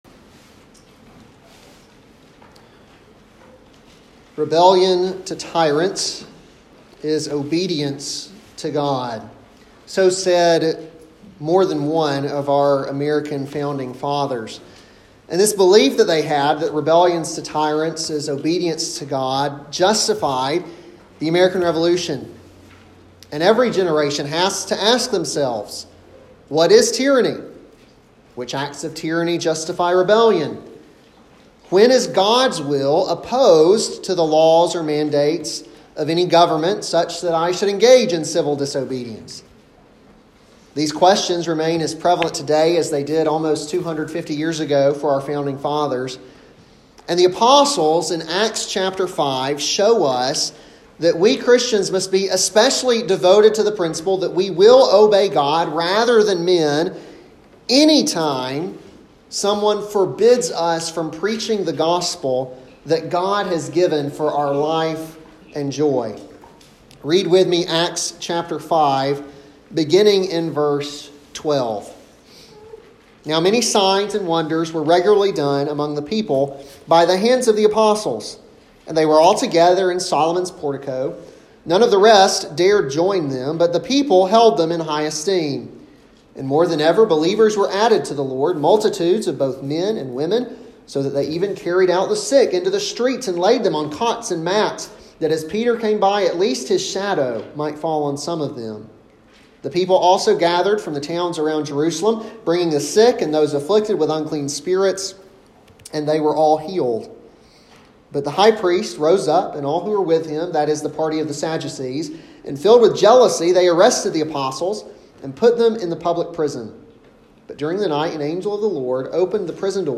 an expository sermon on Acts 5:12-42